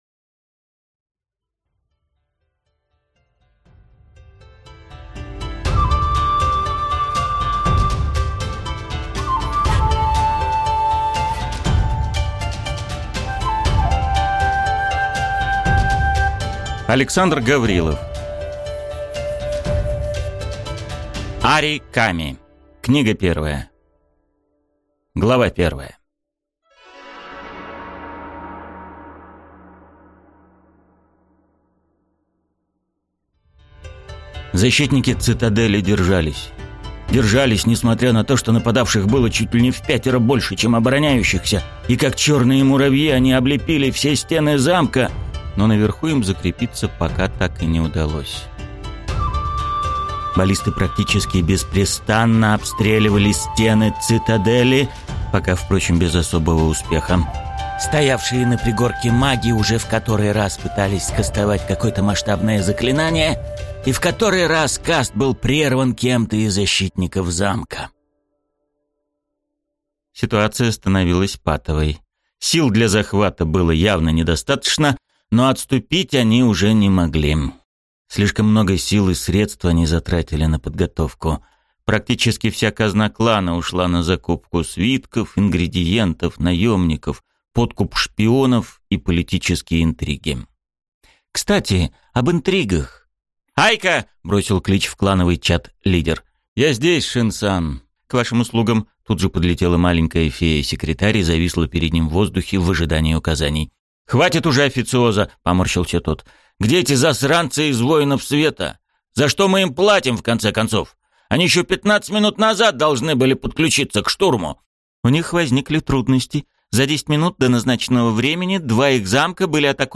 Аудиокнига Ари Ками. Книга 1 | Библиотека аудиокниг
Читает аудиокнигу